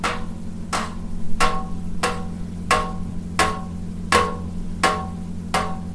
Hiccup
Hiccuping sound effect. Some drunk hiccup at a bar or just walking the street.